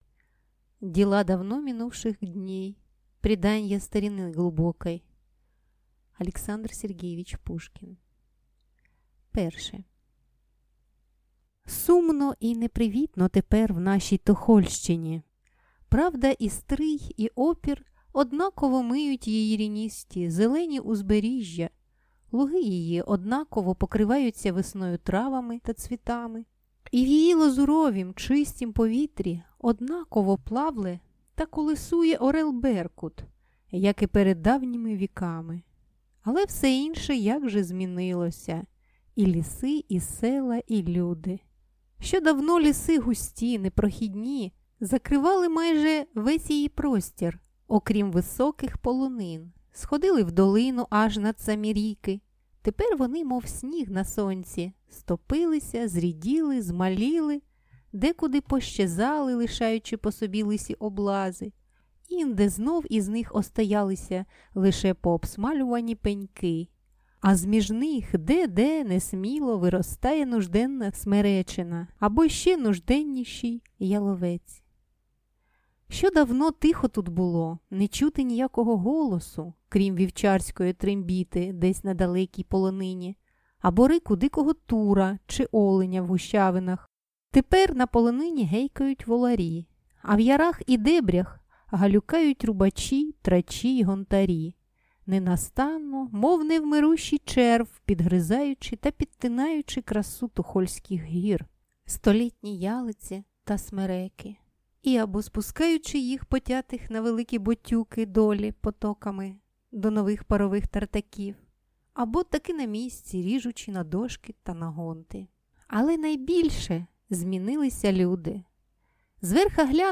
Аудиокнига Захар Беркут - купить, скачать и слушать онлайн | КнигоПоиск